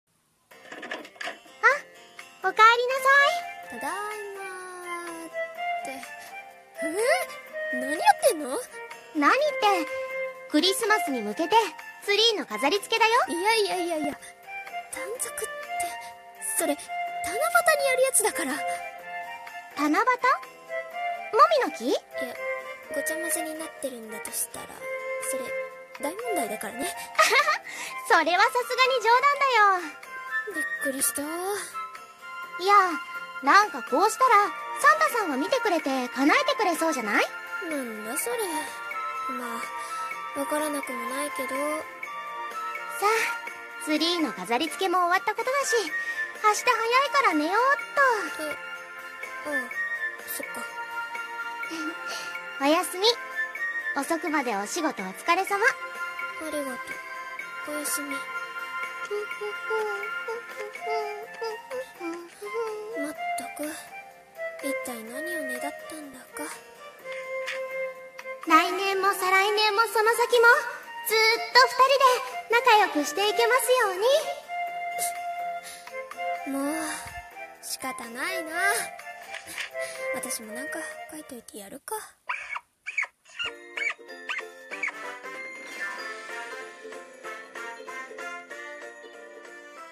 コラボ声劇】メリークリスマスしよ☆